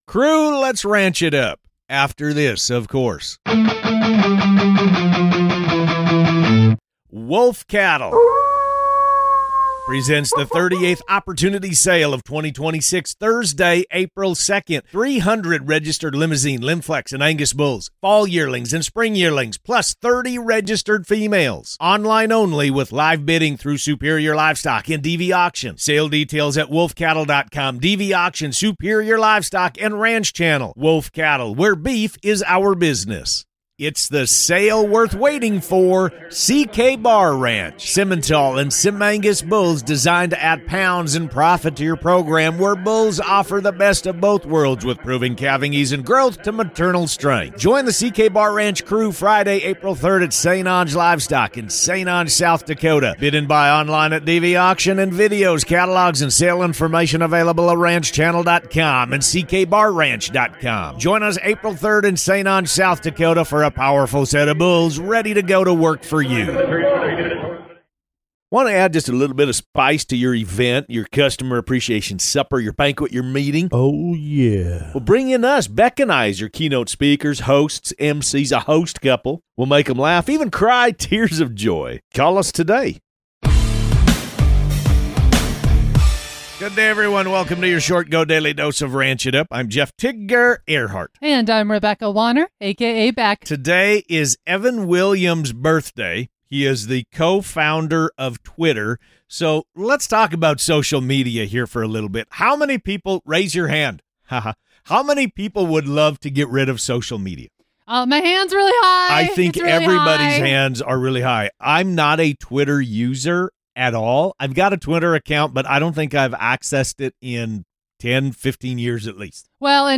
They're serving up the most important ranch-related headlines, from new flavor drops and condiment controversies to the best pairings and fan favorites. Expect insightful (and hilarious) commentary, listener shout-outs, and everything you need to stay in the loop on all things ranch.